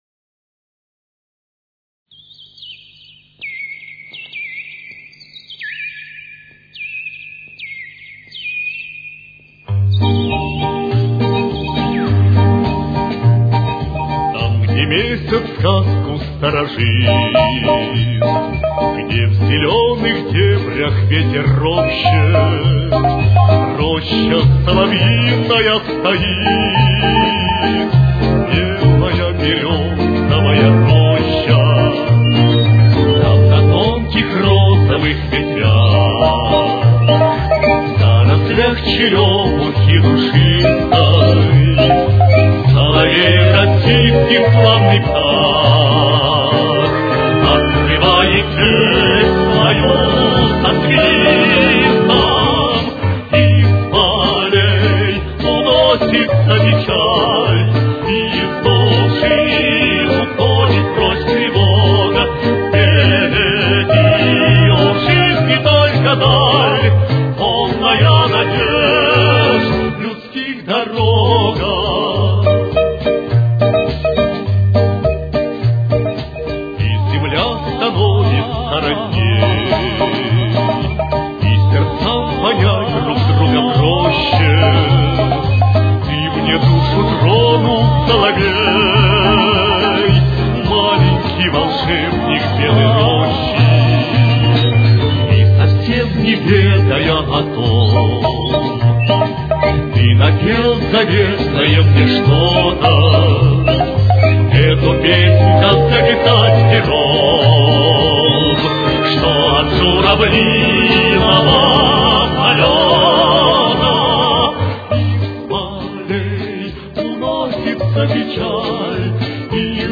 Темп: 106.